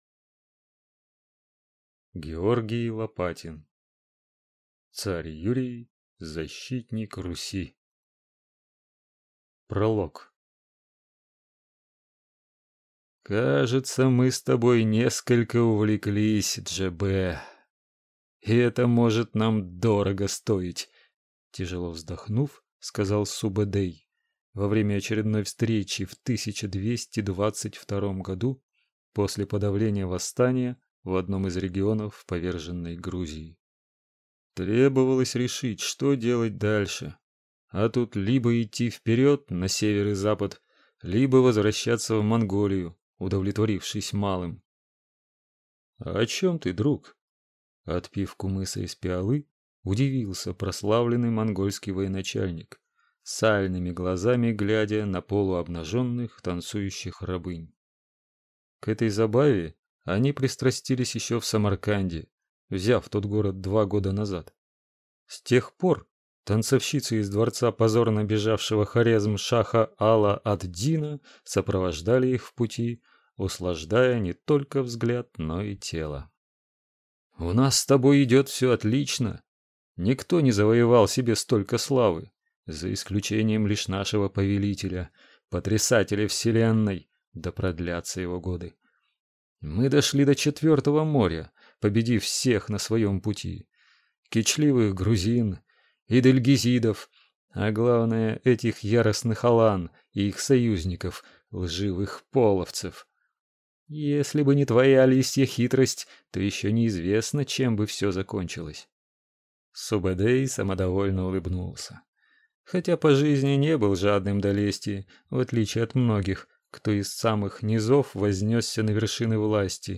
Аудиокнига Царь Юрий. Защитник Руси | Библиотека аудиокниг